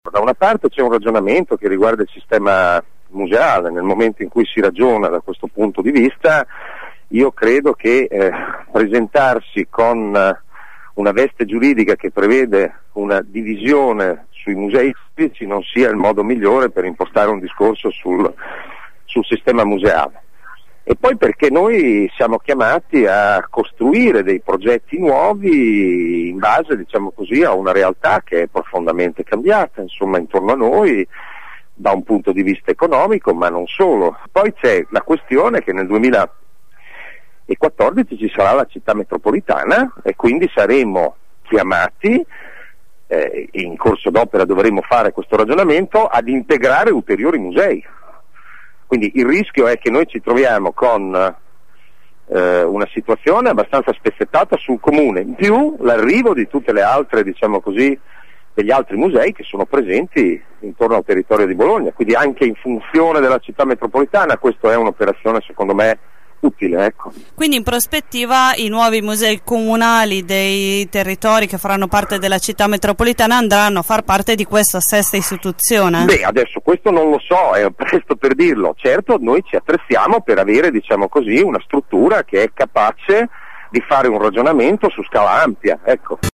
La decisione è presa anche in vista della creazione della città metropolitana, come ha spiegato l’assessore Ronchi, ai nostri microfoni